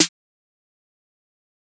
Snare 5.wav